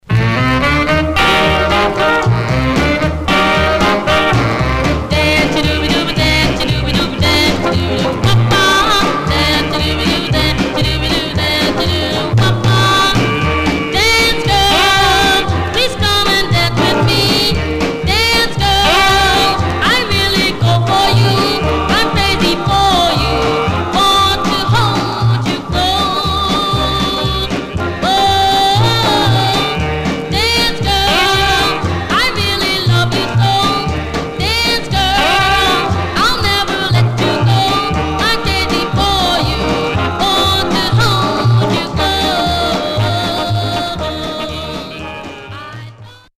Mono
Male Black Group Nat Dist By Gone...